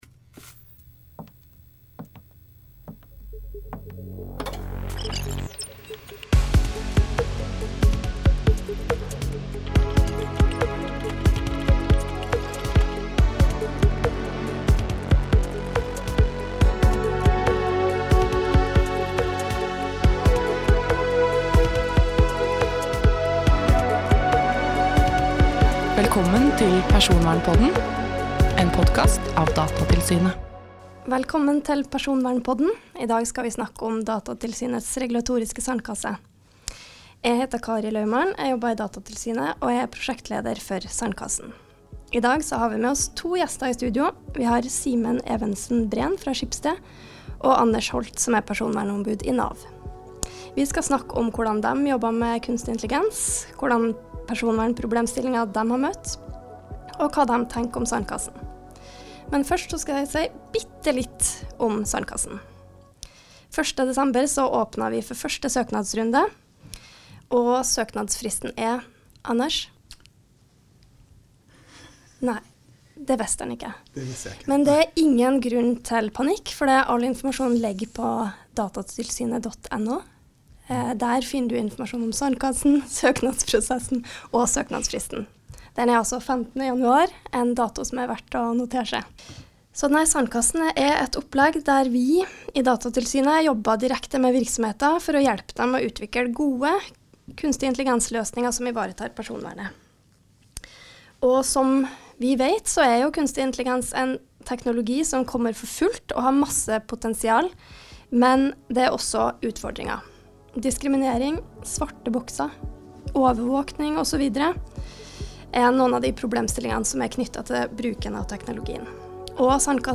I studio